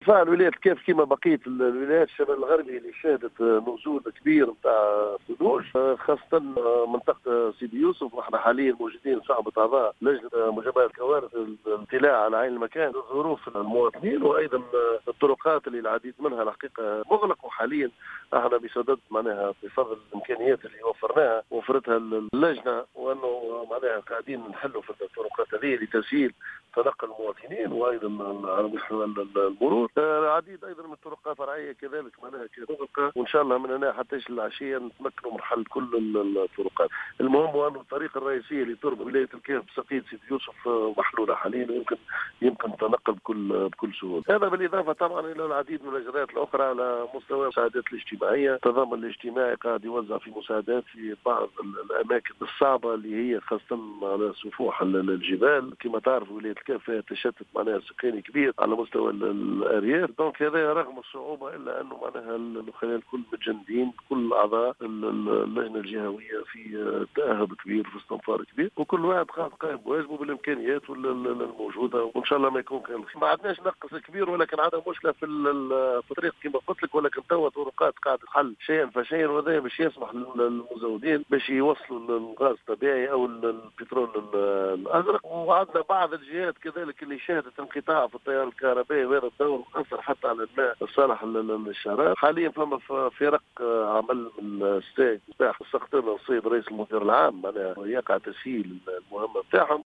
أفاد والي الكاف منور الورتاني، في تصريح للجوهرة اف ام اليوم الجمعة 25 جانفي 2019، بأن الولاية شهدت تساقط كميات كبيرة من الثلوج، وخاصة بساقية سيدي يوسف.